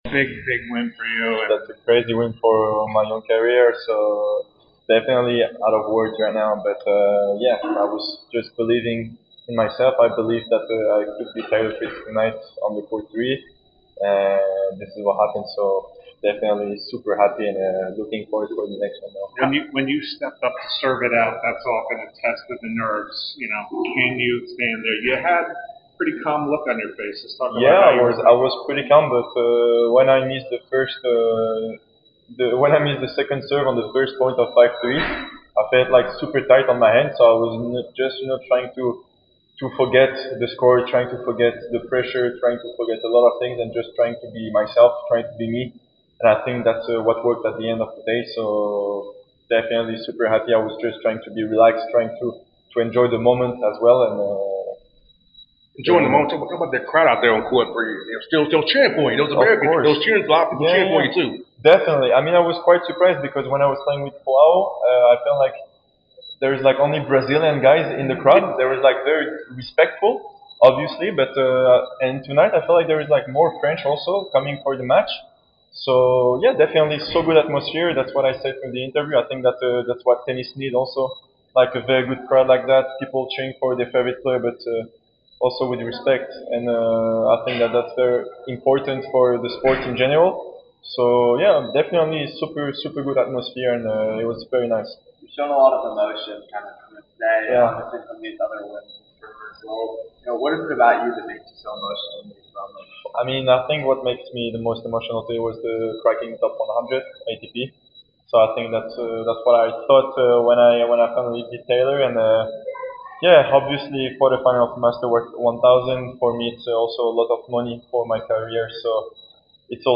08-13-25 Terence Atmane Interview
Terence Atmane post-match interview after defeating Taylor Fritz 3-6. 7-5, 6-3 in the Round of 16 of the Cincinnati Open.